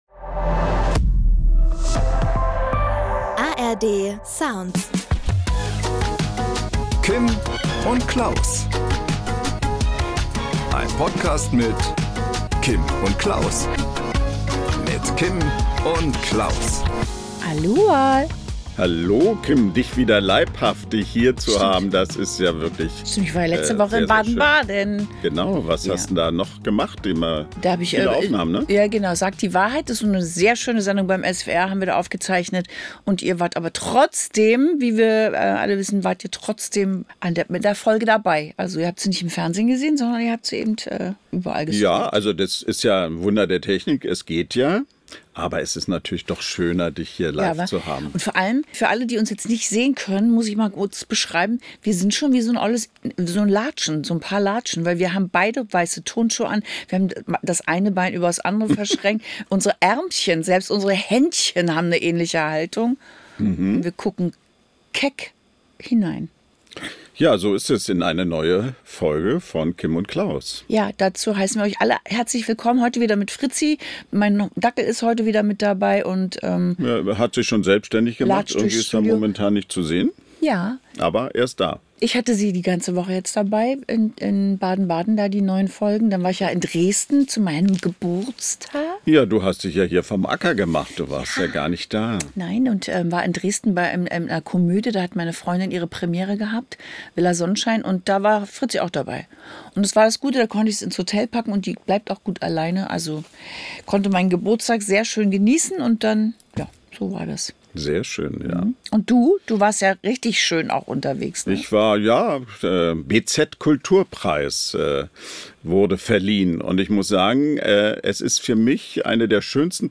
Am Promitelefon: Schauspielerin Dennenesch Zoudé.